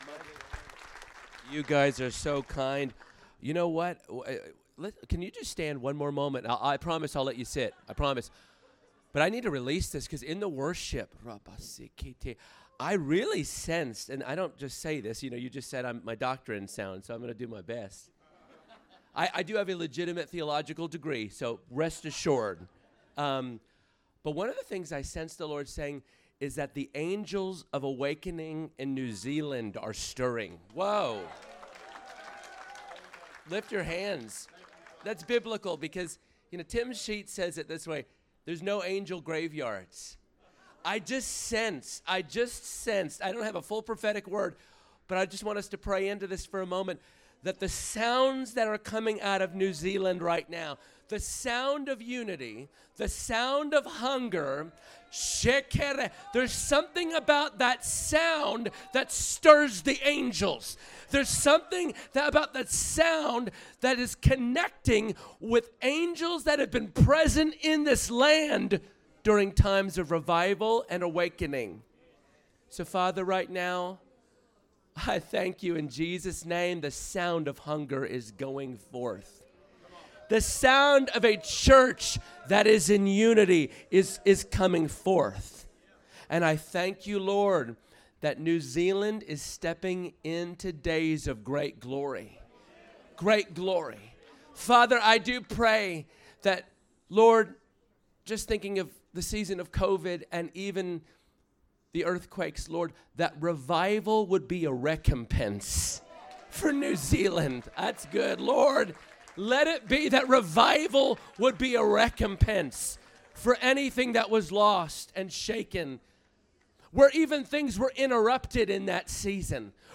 Sermons | Living Waters Christian Centre